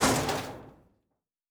Metal Foley 4.wav